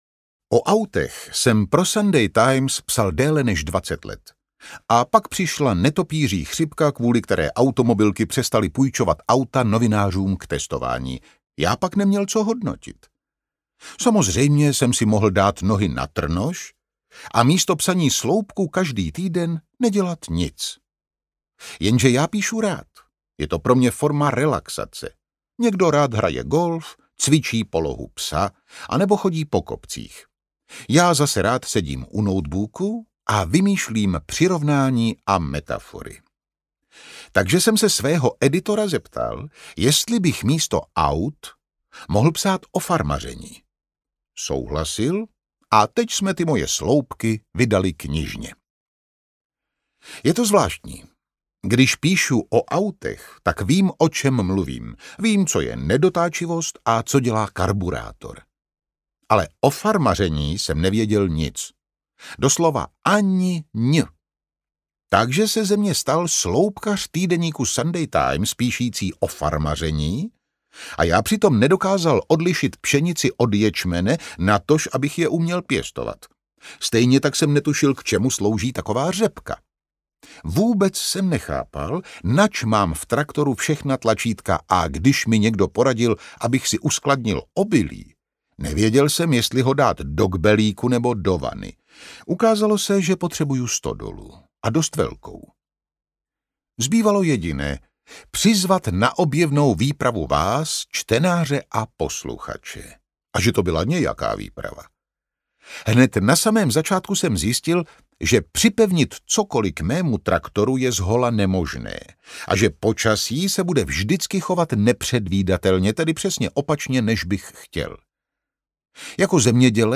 Jeremyho farma nejen zvířat audiokniha
Ukázka z knihy